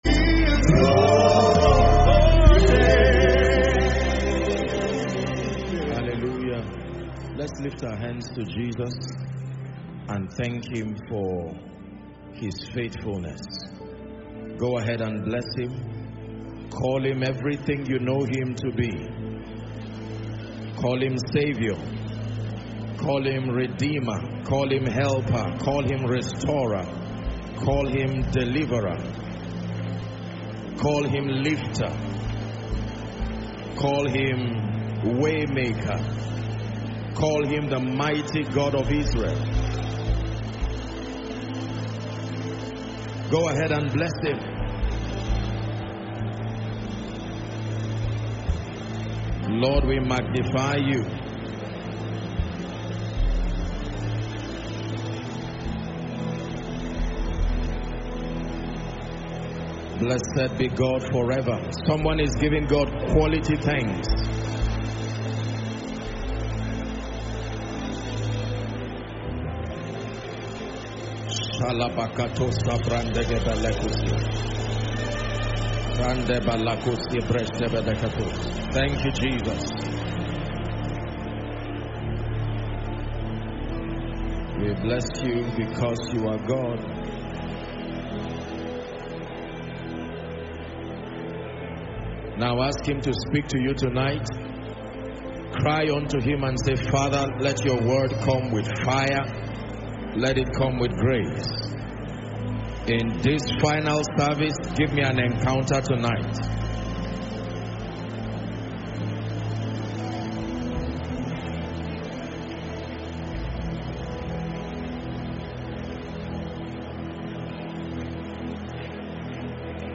Salt and Light (Koinonia Thanksgiving and Impartation Service 2022)